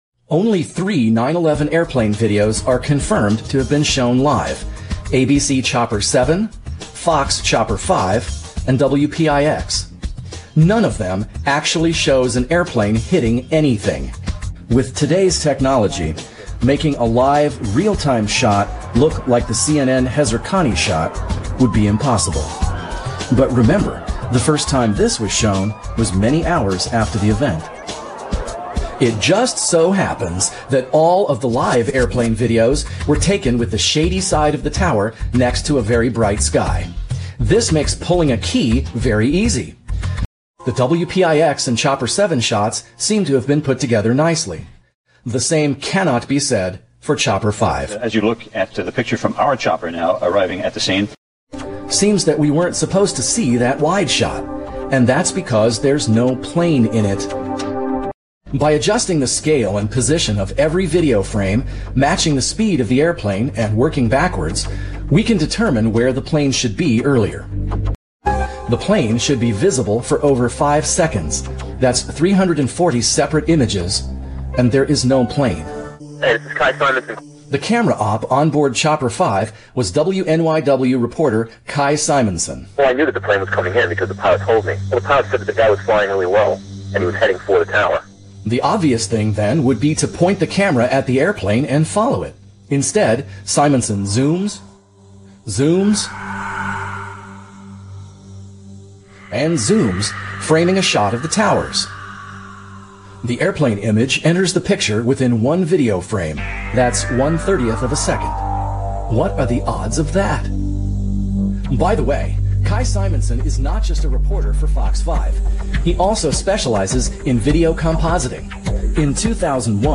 Live TV video proves no planes hit the World Trade Center on 9/11